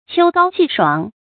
秋高氣爽 注音： ㄑㄧㄡ ㄍㄠ ㄑㄧˋ ㄕㄨㄤˇ 讀音讀法： 意思解釋： 形容秋季天空晴朗；氣候涼爽宜人。